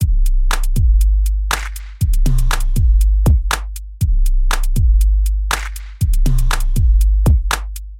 描述：嘻嘻哈哈 寒气逼人等...
Tag: 120 bpm Hip Hop Loops Drum Loops 1.35 MB wav Key : Unknown